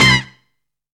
SAD HIT.wav